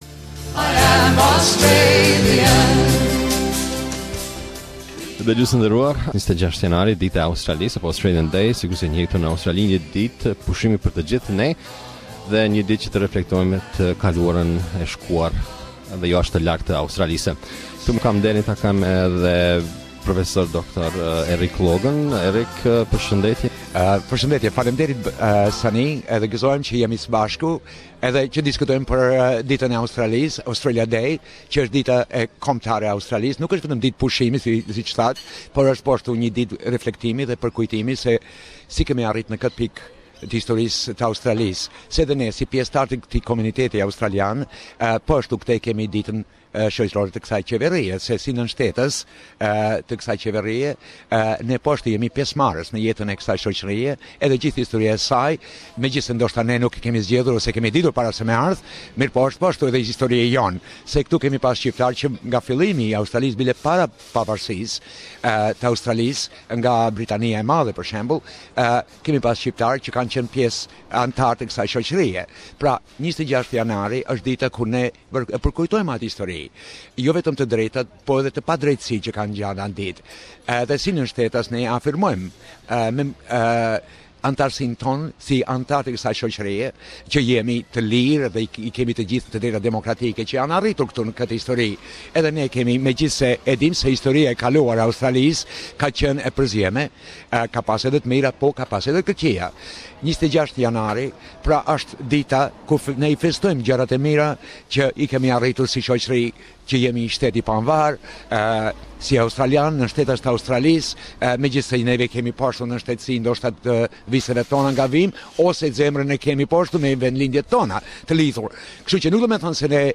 Australia Day Interview